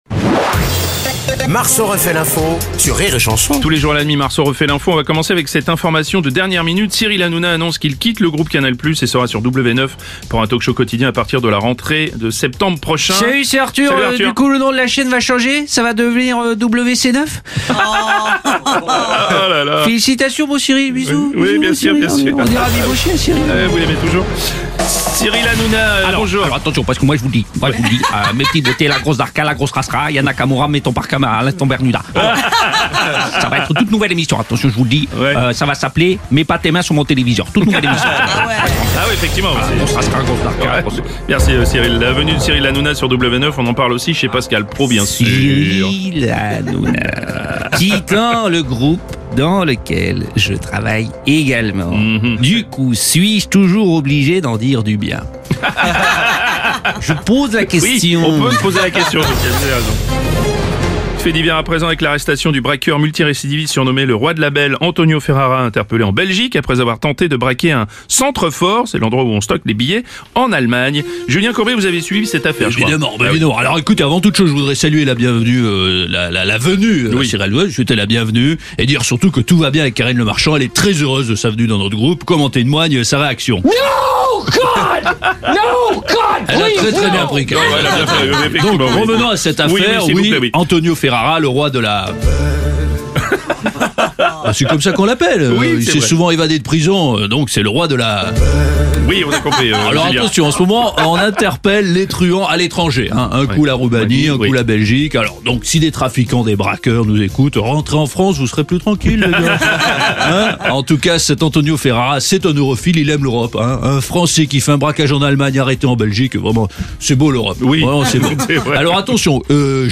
débriefe l’actu en direct à 7h30, 8h30, et 9h30.